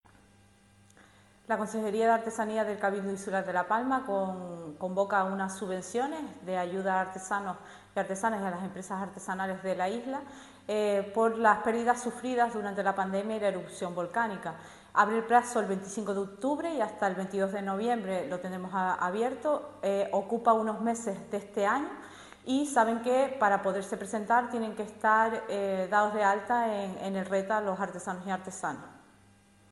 Declaraciones audio Susana Machín subvenciones.mp3